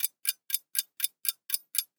TickTock 02.wav